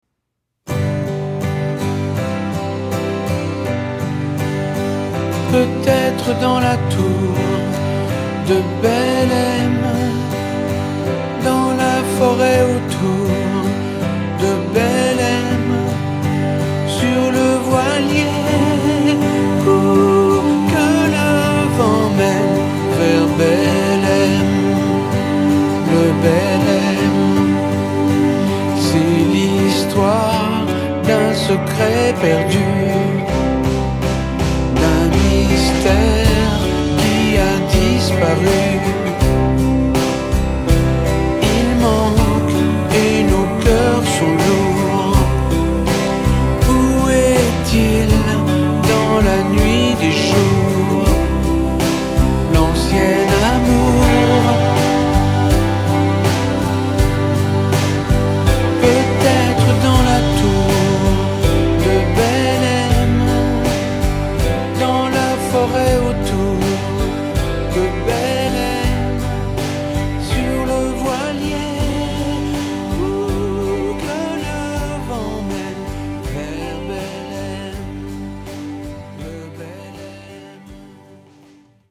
Tonalité SOl majeur